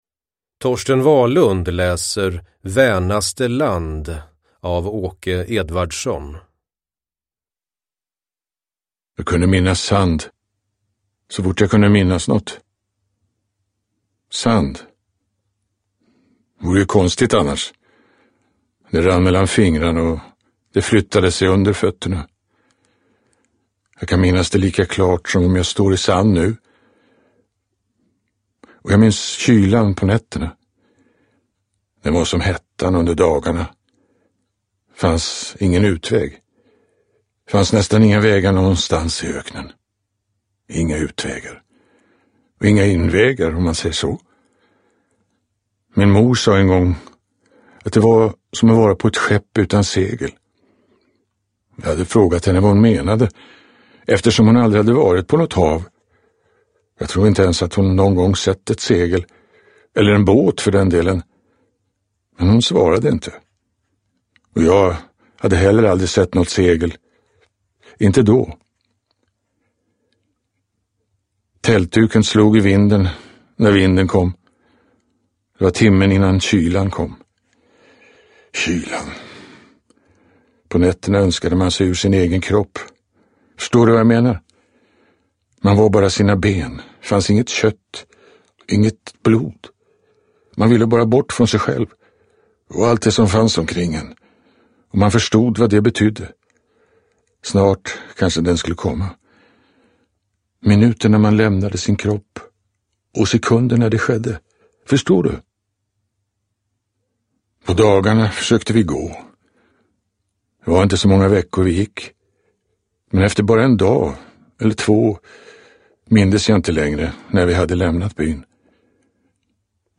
Vänaste land – Ljudbok – Laddas ner
Uppläsare: Torsten Wahlund